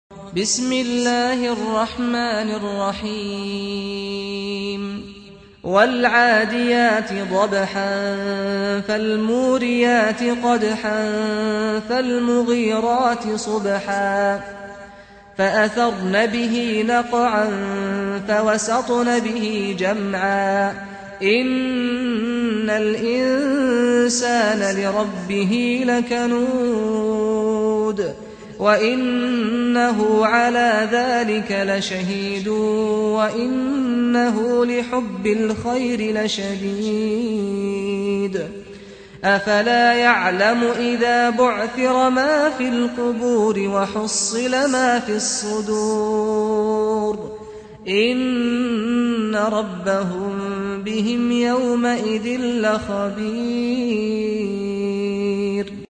سُورَةُ العَادِيَاتِ بصوت الشيخ سعد الغامدي